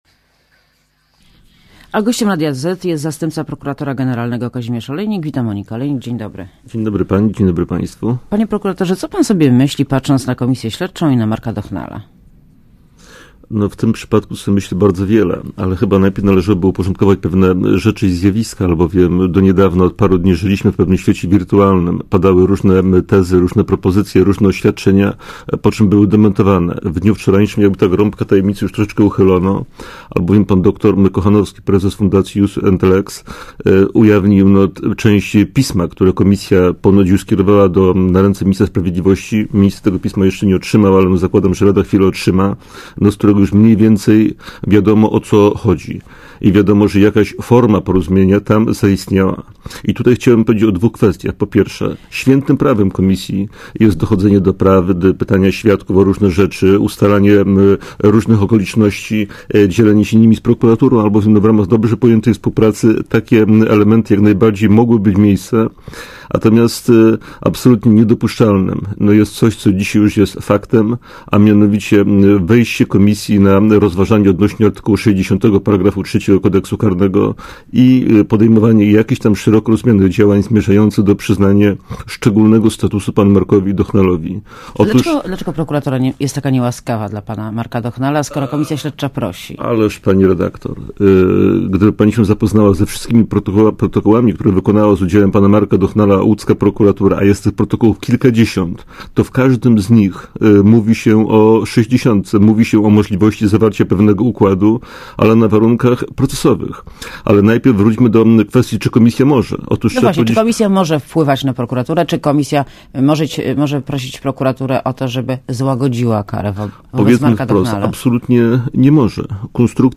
* Posłuchaj wywiadu * Gościem Radia ZET jest zastępca prokuratora generalnego Kazimierz Olejnik, wita Monika Olejnik.